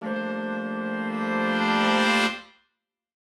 Index of /musicradar/gangster-sting-samples/Chord Hits/Horn Swells
GS_HornSwell-Gmin+9sus4.wav